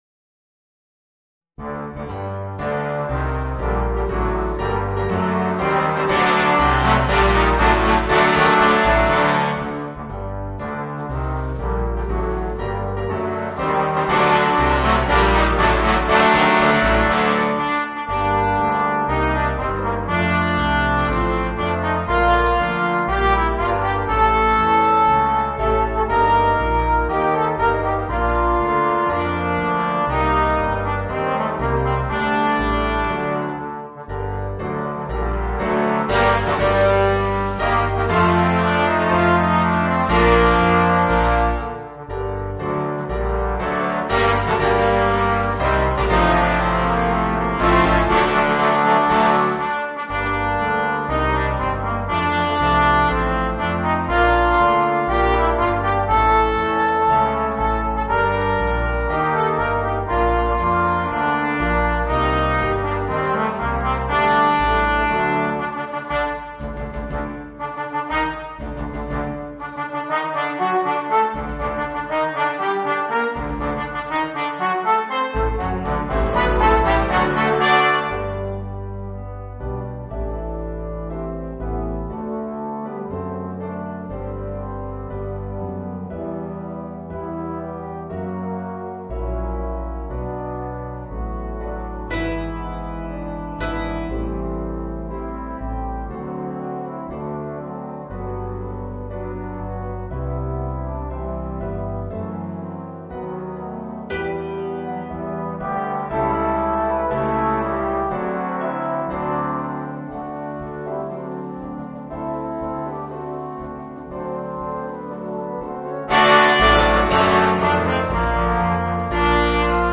Voicing: 4 Trombones and Rhythm Section